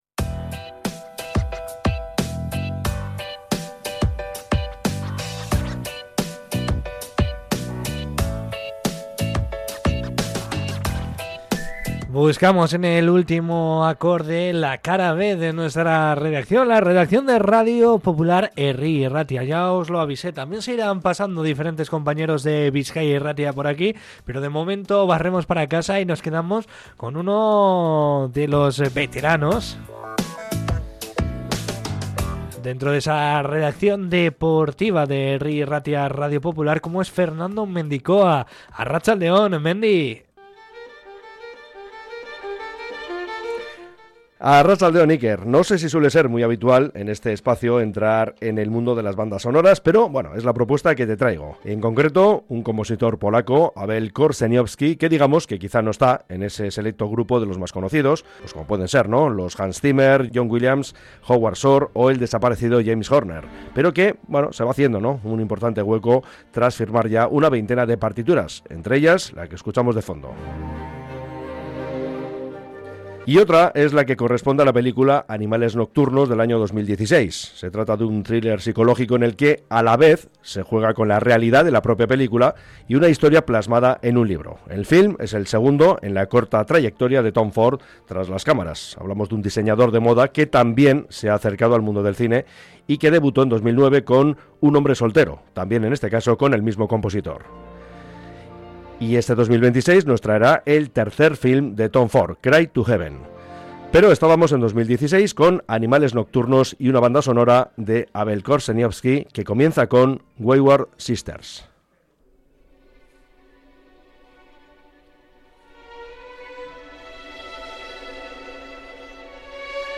Aunque él mismo admite que no suele ser habitual entrar en este género en el programa, su propuesta ha traído un aire de sofisticación y tensión narrativa al estudio.
La pieza que hemos escuchado es Wayward Sisters, el tema que abre la banda sonora de la película Animales Nocturnos (2016).
Una recomendación que demuestra que la tensión de un buen thriller también tiene su propio y elegante ritmo musical.